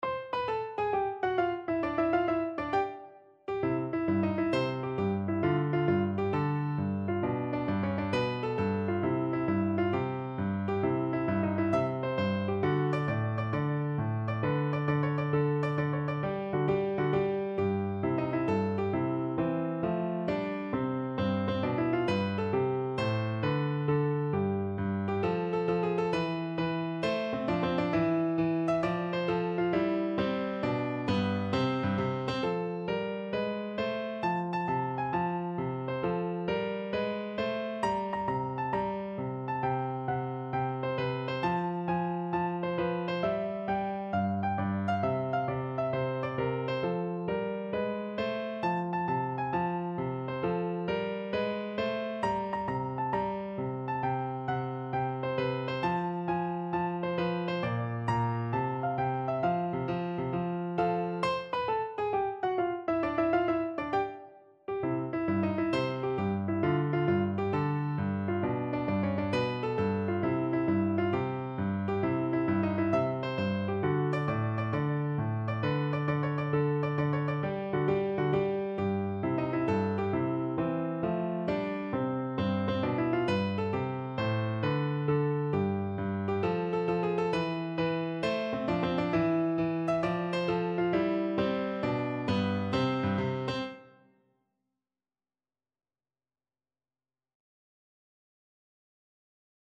Piano version
= 200 Allegro con brio (View more music marked Allegro)
6/8 (View more 6/8 Music)
Piano  (View more Easy Piano Music)
Classical (View more Classical Piano Music)